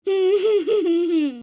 Free SFX sound effect: Toddler Laugh.
Toddler Laugh
Toddler Laugh.mp3